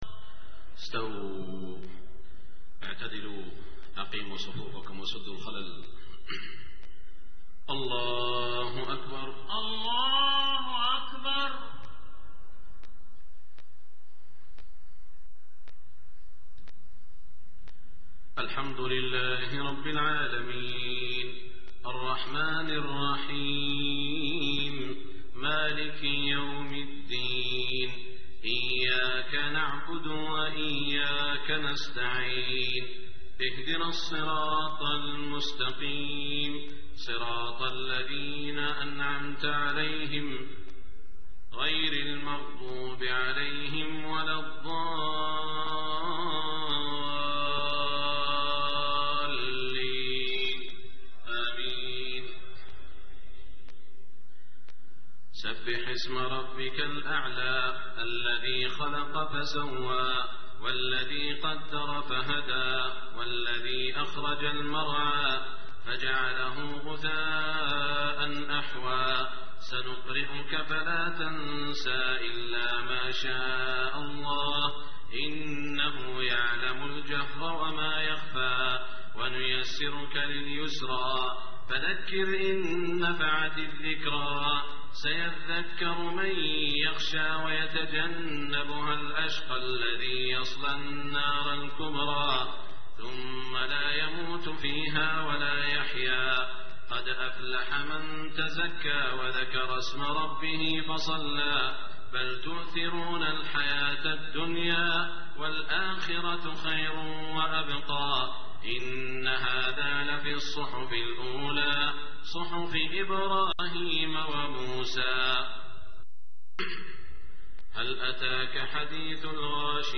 صلاة الجمعة 7-7-1426 سورتي الأعلى و الغاشية > 1426 🕋 > الفروض - تلاوات الحرمين